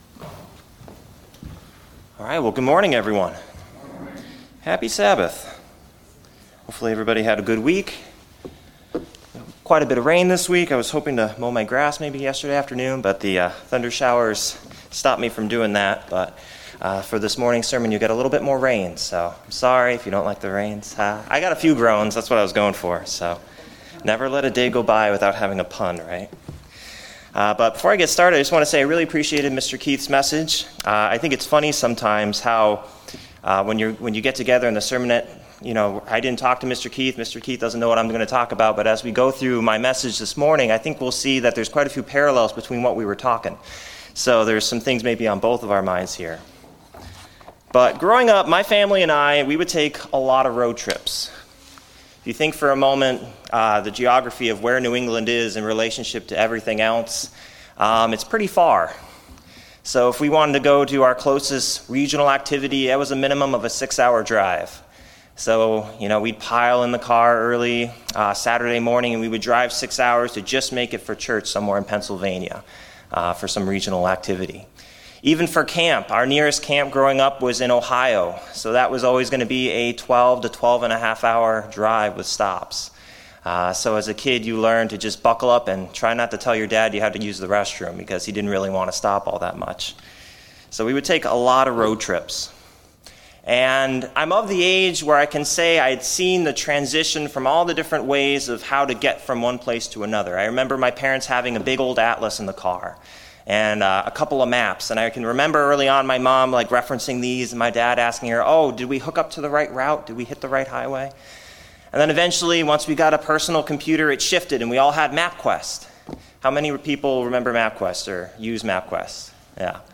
Given in Murfreesboro, TN